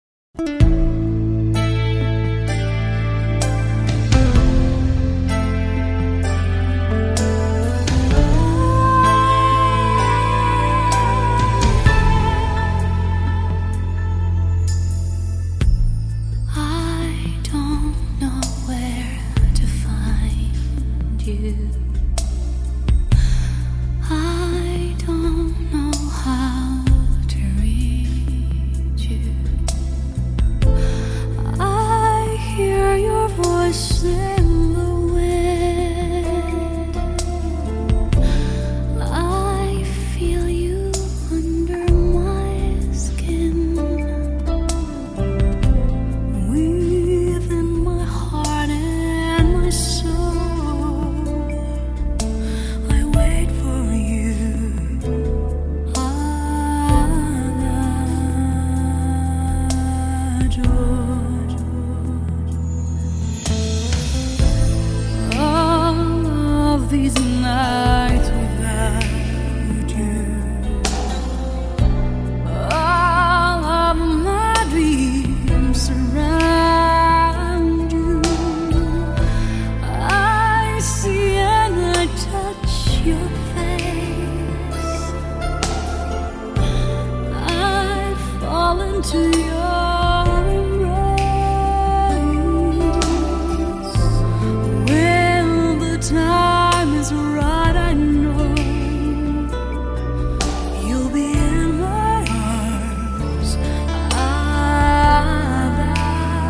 [1730] 차분해 지고 싶을 때 들으면 좋은 노래..